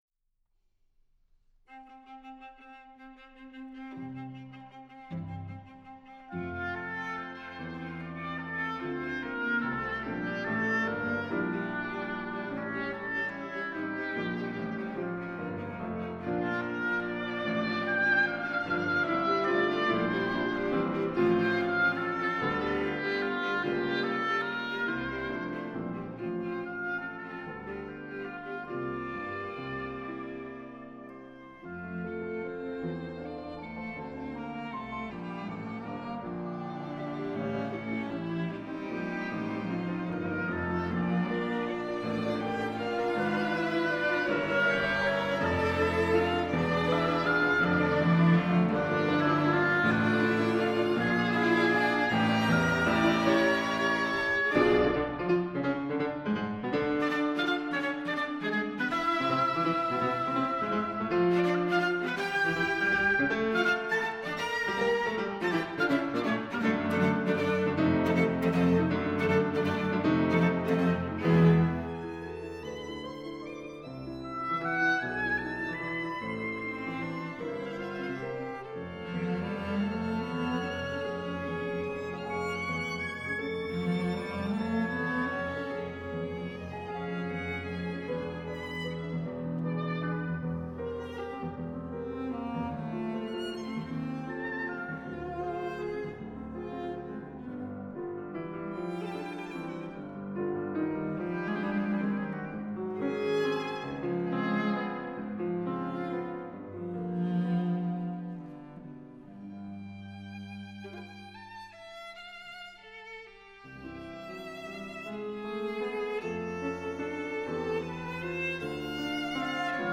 موسیقی کلاسیک: پیانو کوئینتت از تئودور دوبوآ - Théodore Dubois - Piano Quintet in F major (for oboe, violin, viola, cello and piano)
piano_quintet_f_major.mp3